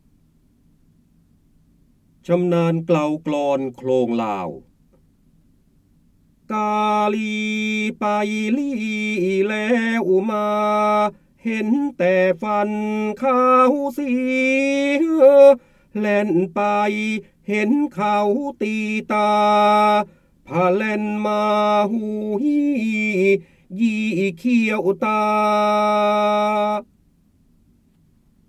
เสียงบรรยายจากหนังสือ จินดามณี (พระโหราธิบดี) ชำนาญเกลากลอนโคลงลาว
คำสำคัญ : จินดามณี, พระโหราธิบดี, ร้อยกรอง, ร้อยแก้ว, พระเจ้าบรมโกศ, การอ่านออกเสียง